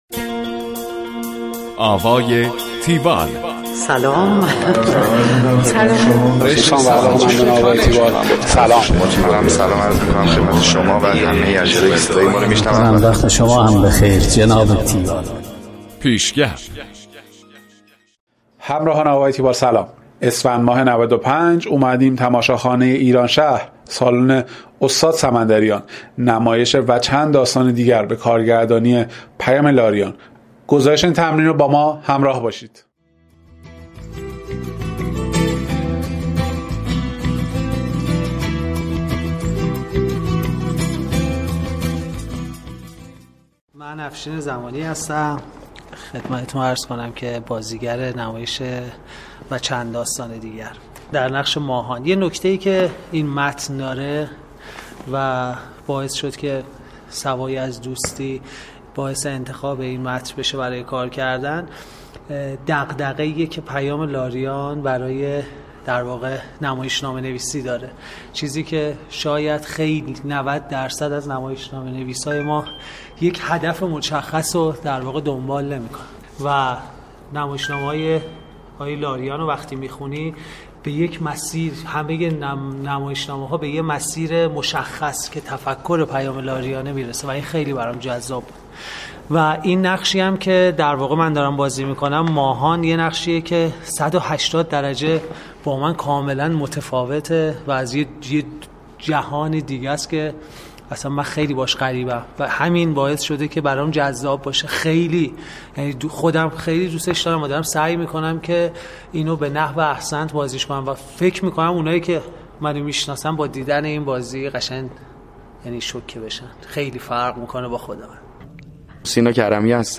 گزارش آوای تیوال از نمایش و چند داستان دیگر